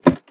assets / minecraft / sounds / step / ladder2.ogg
ladder2.ogg